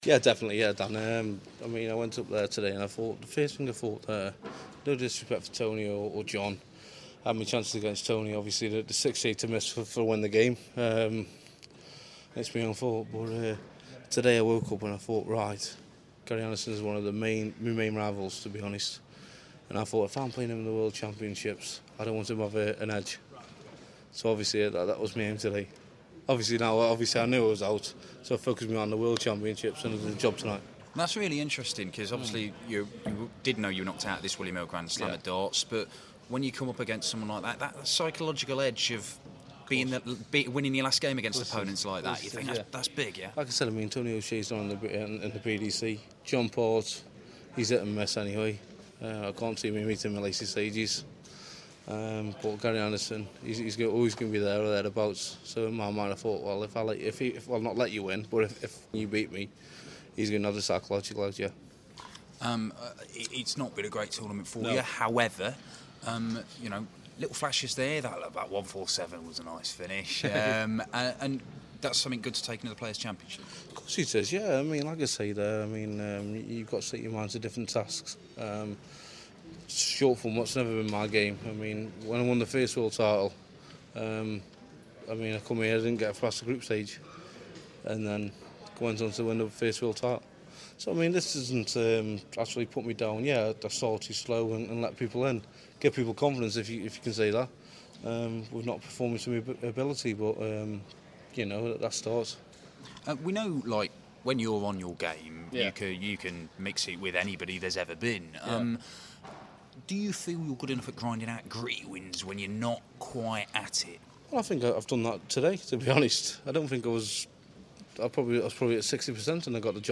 William Hill GSOD - Lewis Interview (3rd game)